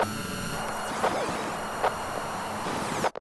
nerfs_psynoise8.ogg